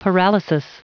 Prononciation du mot paralysis en anglais (fichier audio)
Prononciation du mot : paralysis